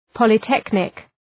Shkrimi fonetik {,pɒlı’teknık}